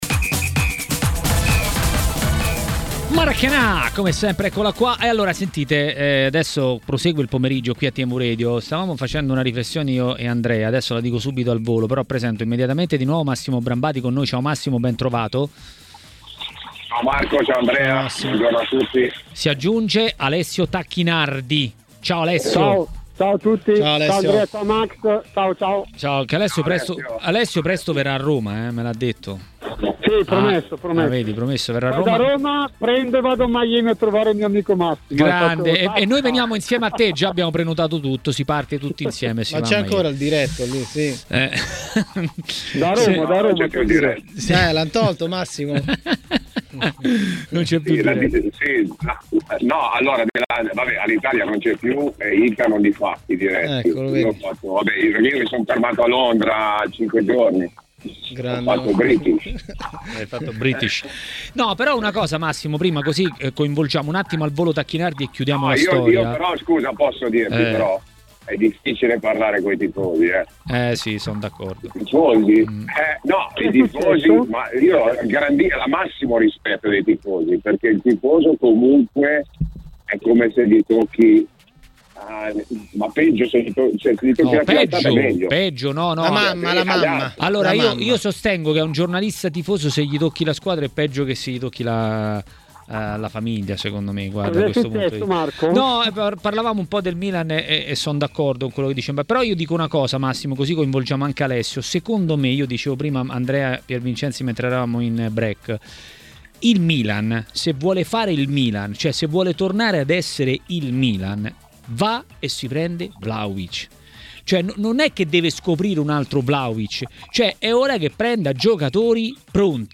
L'ex calciatore e tecnico Alessio Tacchinardi a Maracanà, nel pomeriggio di TMW Radio, ha parlato del turno di campionato.